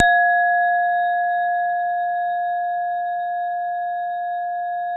WHINE   F3-L.wav